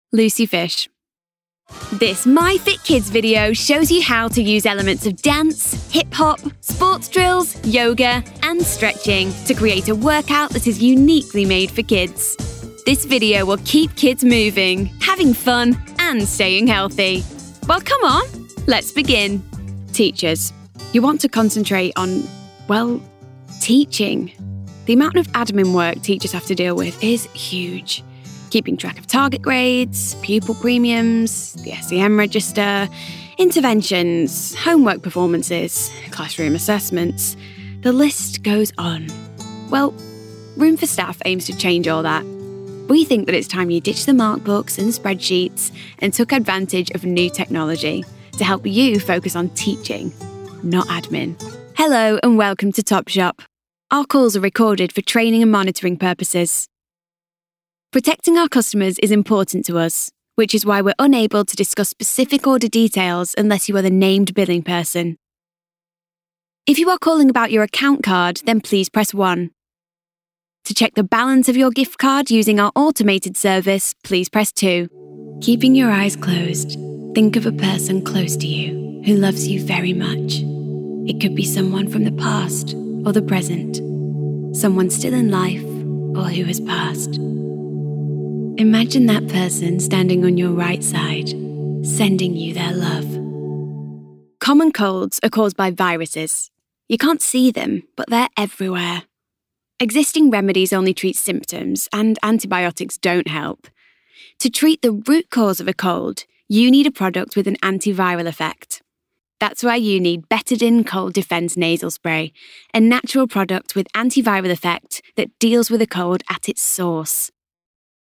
Female
British English (Native)
Bright, Bubbly, Confident, Cool, Friendly, Natural, Young
Northern (native), Manchester, Lancashire, Yorkshire, Liverpool, RP/Standard, Heightened RP, Essex, Cockney, US general, US Southern states
Commercial Reel.mp3
Microphone: Neumann TLM 103
Audio equipment: Studiobricks booth, Presonus audiobox, Macbook wired to silent screen inside the booth